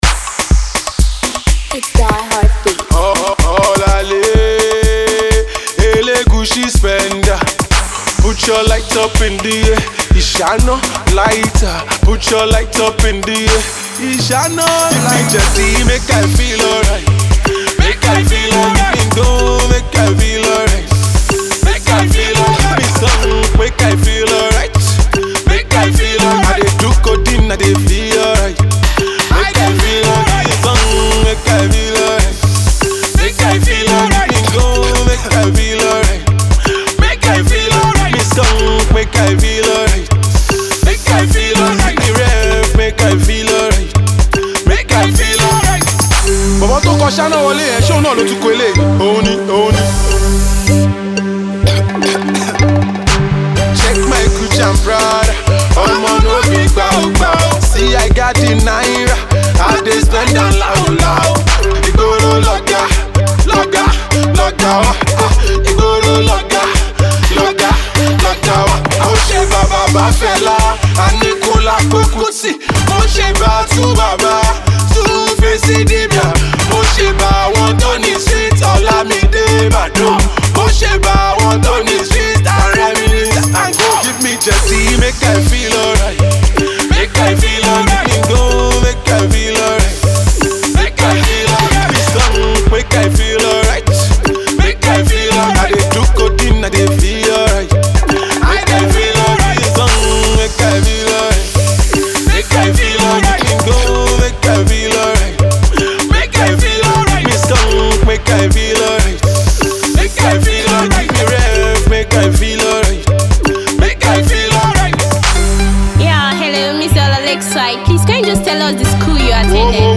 Indigenous Pop